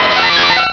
Cri d'Électhor dans Pokémon Rubis et Saphir.